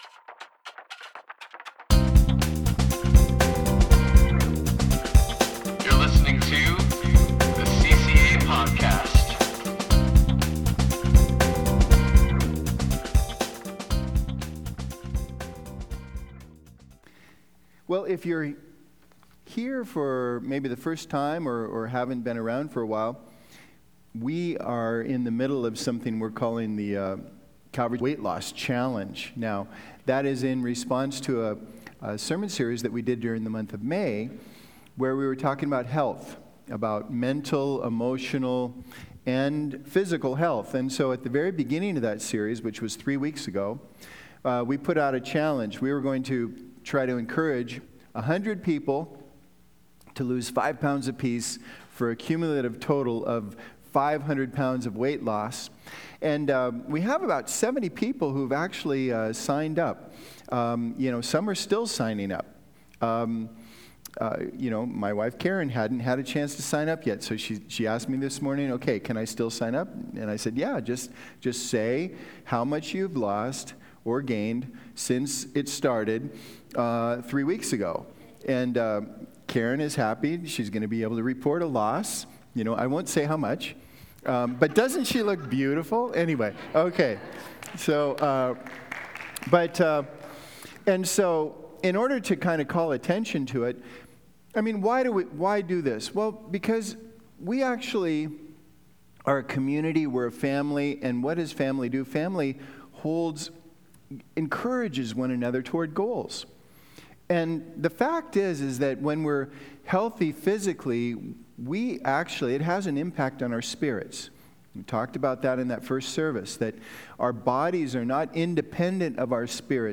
The June Sermon series focuses on Social Justice - from poverty to racism, we’ll examine these pressing issues before us along with the help of guest speakers.